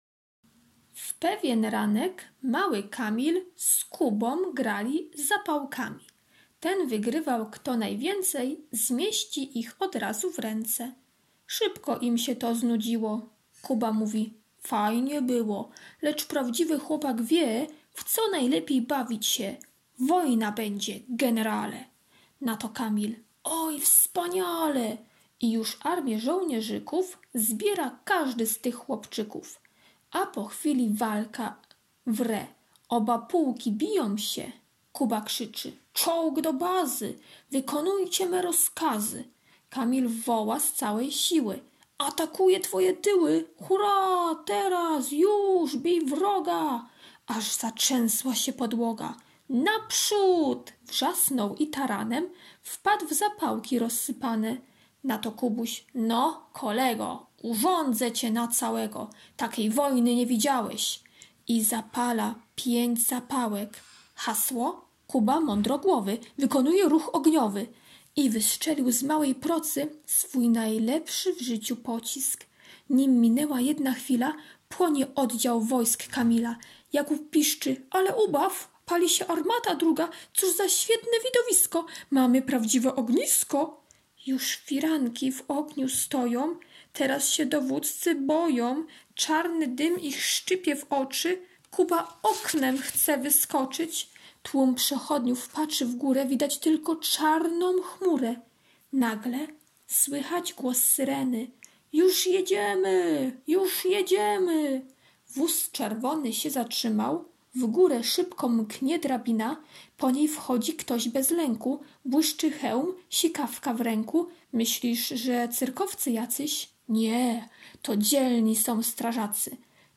środa - opowiadanie [4.39 MB] środa - prezentacja o strażakach [12.94 MB] środa - ćw. dla chętnych - kolorowanka wg kodu [168.50 kB] środa - ćw. dla chętnych - kolorowanka "Dzielny strażak" [439.00 kB] środa - ćw. dla chętnych - karta pracy "Wóz strażacki" [222.50 kB] środa - ćw. dla chętnych - litera D, d [111.64 kB]